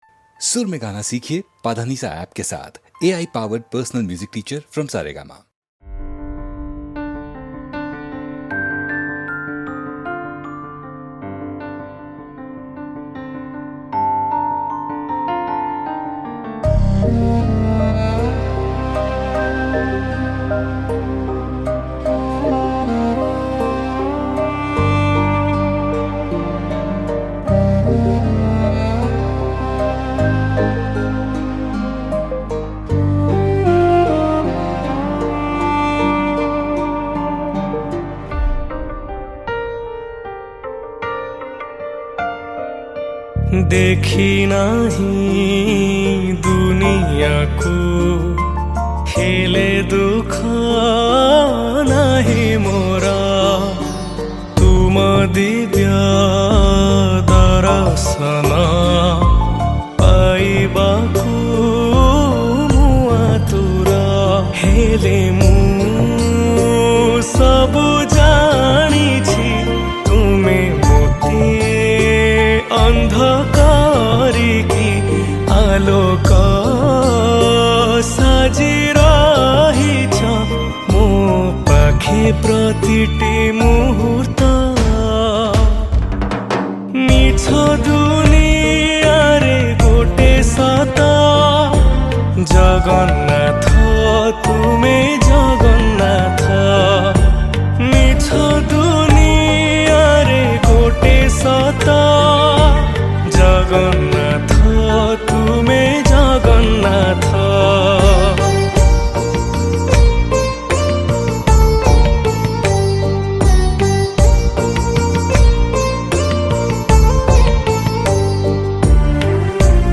Odia Bhajan Song 2025 Songs Download